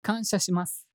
感謝 ボイス 声素材 – Gratitude Voice
Voiceボイス声素材